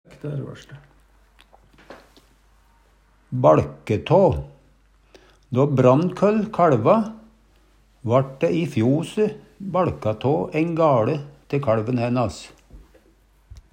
DIALEKTORD PÅ NORMERT NORSK baLke tå bolke av, setje opp skillevegg, dele frå eit rom Eksempel på bruk Då Brannkøll kaLva vaRt dæ baLka tå æin gaLe i fjose te kaLven hænas. Høyr på uttala Ordklasse: Uttrykk Attende til søk